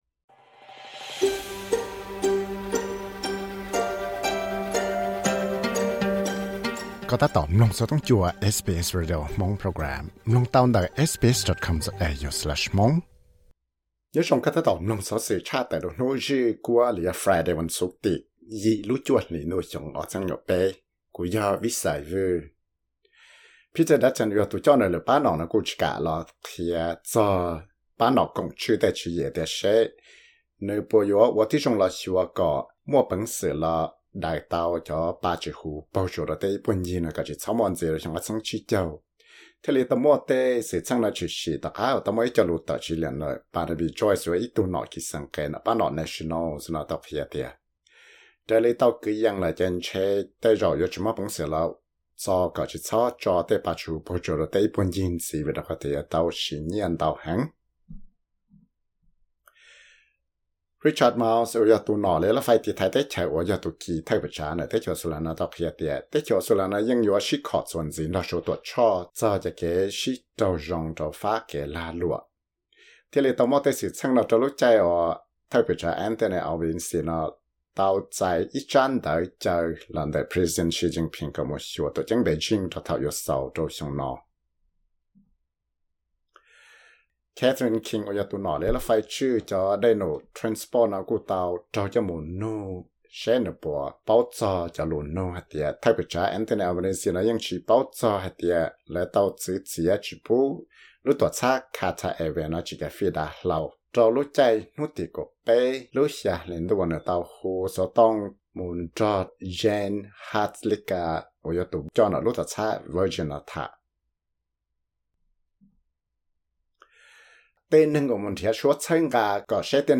Credit: SBS studio - SBS